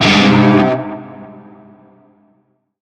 NarmerShawzinMinChordK.ogg